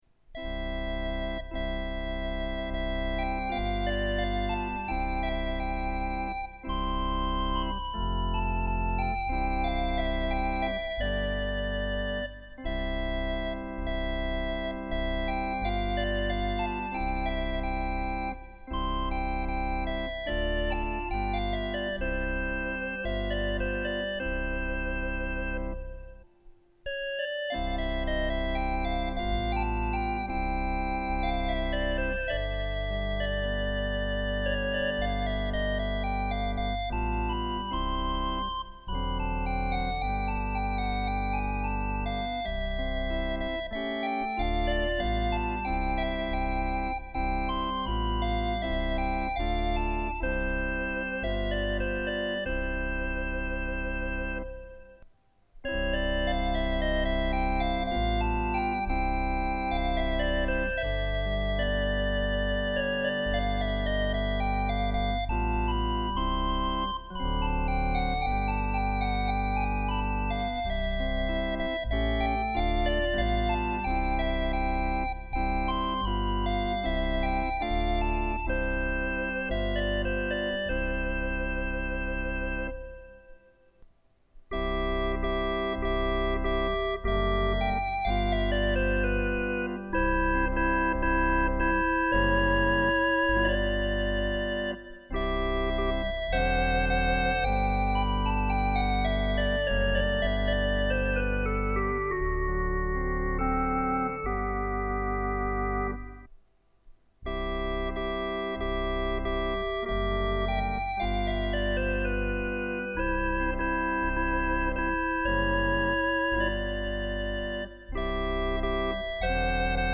Post tonewheel – Concorde
Ebb Tide — Once upon a Time in the West (x2) — Raindrops keep falling on my head — Mozart: petite musique de nuit. Practice tunes from a slow learner, 1980.